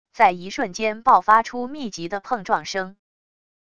在一瞬间爆发出密集的碰撞声wav音频